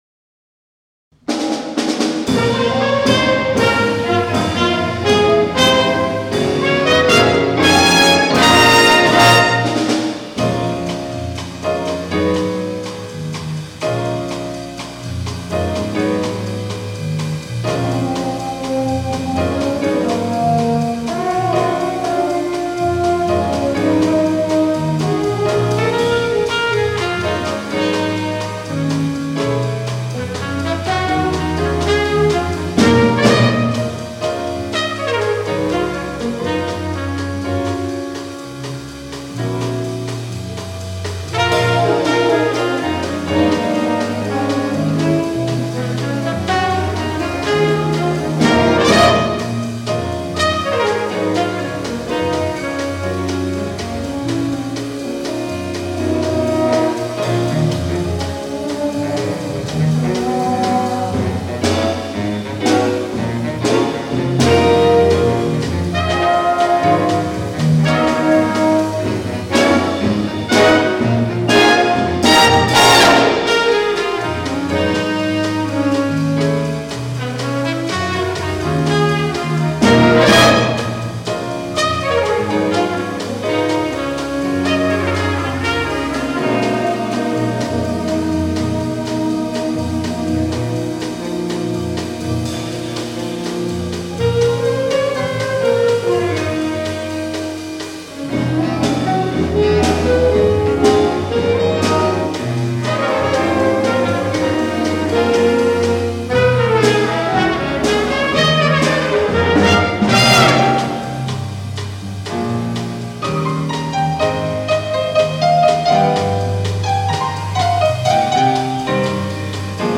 Jazz Concert 2023 Recordings